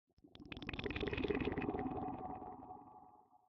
Minecraft Version Minecraft Version 1.21.5 Latest Release | Latest Snapshot 1.21.5 / assets / minecraft / sounds / block / conduit / short3.ogg Compare With Compare With Latest Release | Latest Snapshot